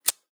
zippo_strike_fail_01.wav